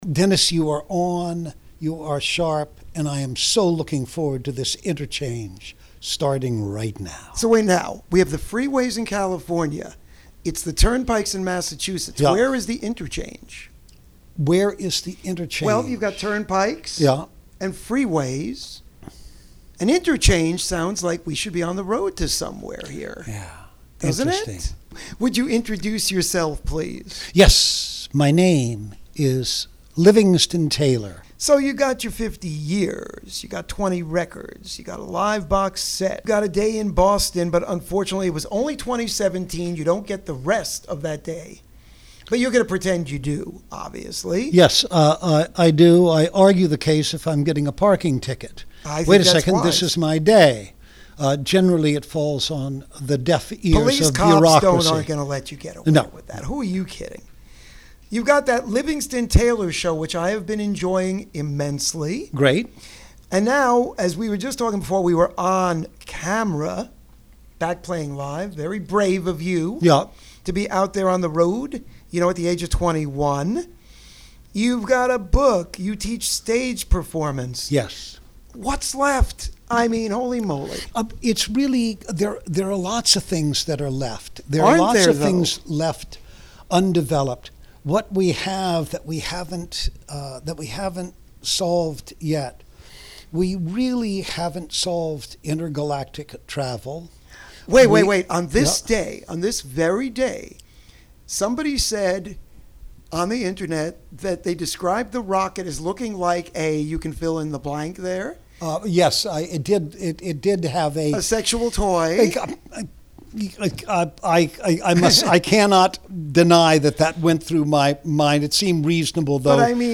This Week's Interview (08/01/2021): Livingston Taylor LISTEN TO THE INTERVIEW